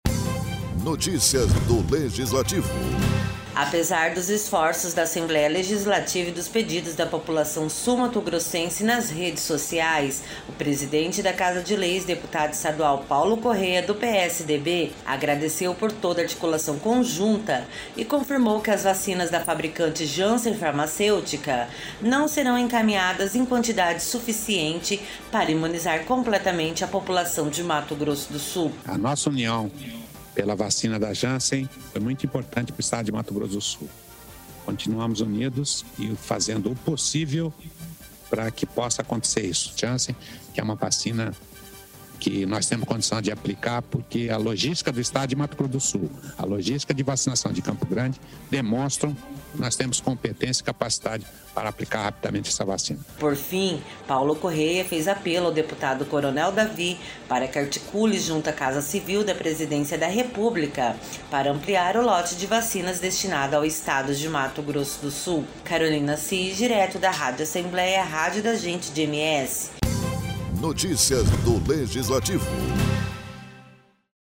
O presidente da ALEMS, deputado estadual Paulo Corrêa, do PSDB, agradeceu por toda a articulação conjunta entre os deputados e outras entidades que se uniram pela campanha para que o Estado recebesse doses da vacina contra covid-19 da Janssen Farmacêutica, e confirmou que as vacinas não serão encaminhadas em quantidade suficiente para imunizar completamente a população de Mato Grosso do Sul.